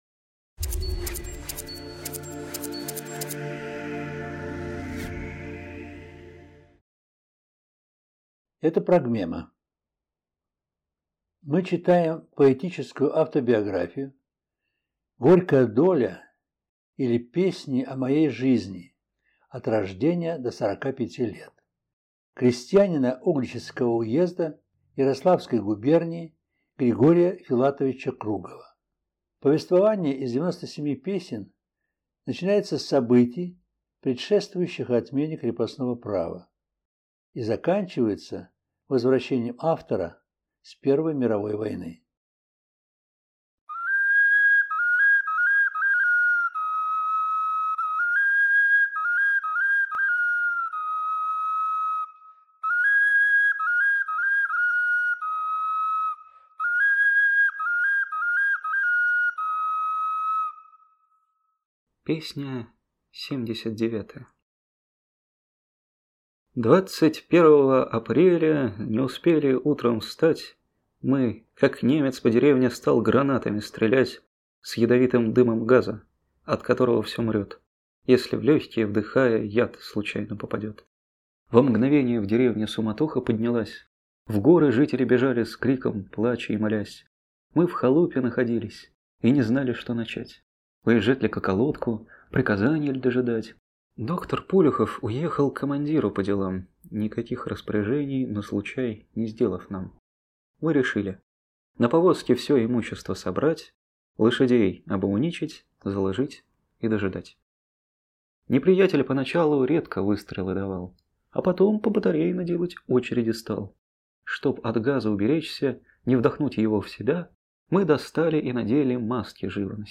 В записи использованы звучание глиняных Скопинских, Суджанских, Чернышенских игрушек-окарин
Колыбельная "Баю-баюшки-баю".